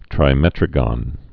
(trī-mĕtrə-gŏn)